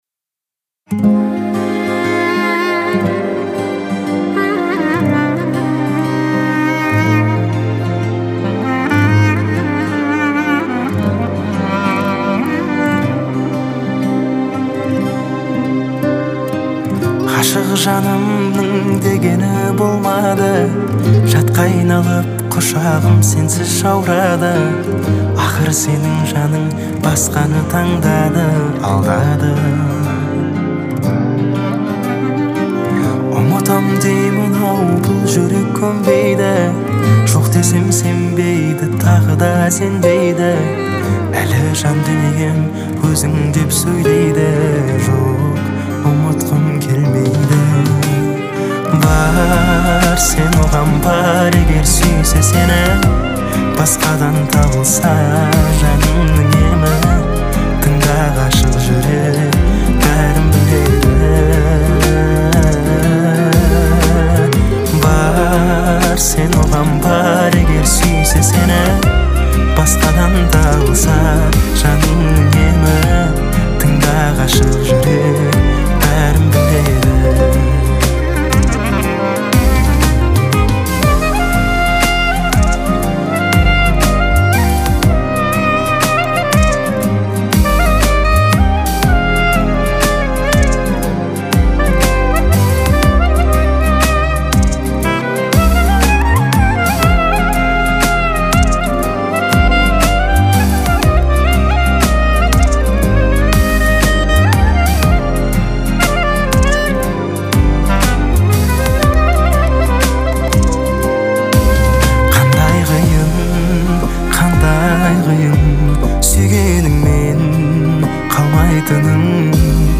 Качество: 320 kbps, stereo
Казахская музыка